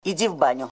• Качество: 320, Stereo
голосовые
советское кино